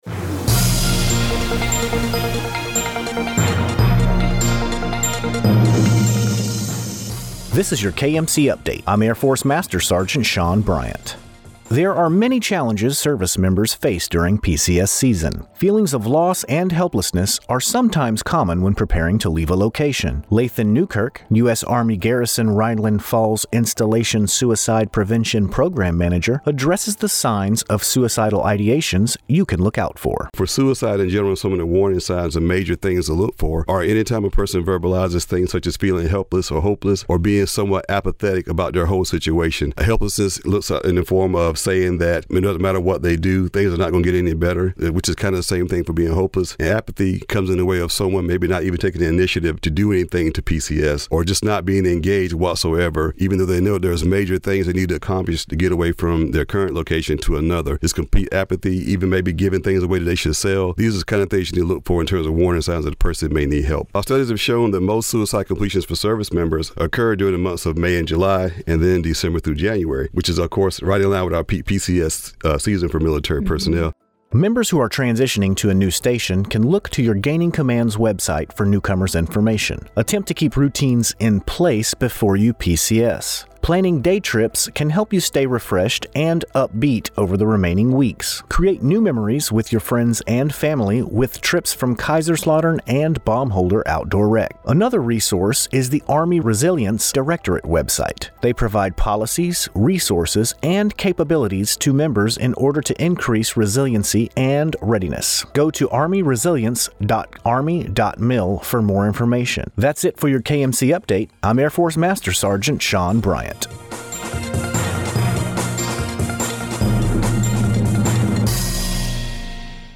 And additional resource for military members is the Army Resilience Directorate; they provide policies, resources and capabilities to increase resilience within the force. (DMA Radio Newscast